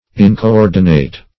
Incoordinate \In`co*["o]r"di*nate\
incoordinate.mp3